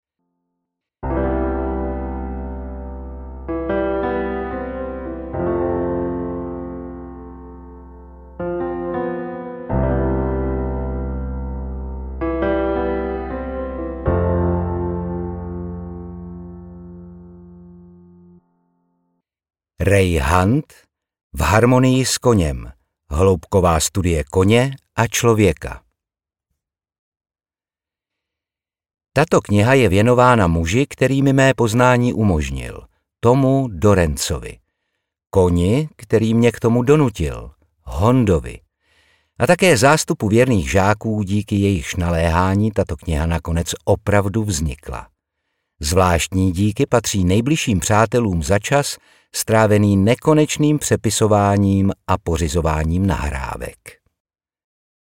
V harmonii s koněm audiokniha
Ukázka z knihy
• InterpretOtakar Brousek ml.